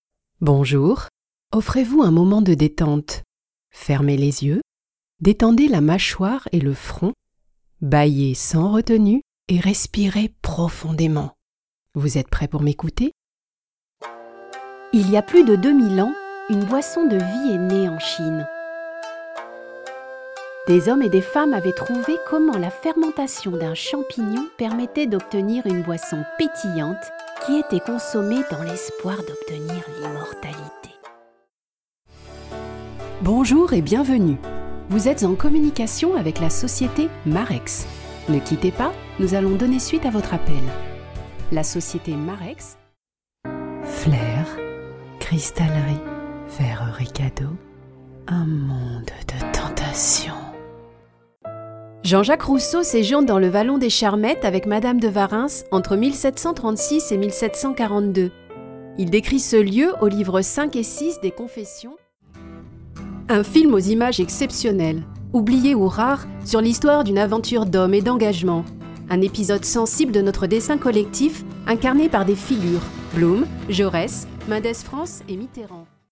Young, warm and smooth. French native speaker, german and English spoken
Sprechprobe: Sonstiges (Muttersprache):
My voice is a young female voice, that is friendly and warm.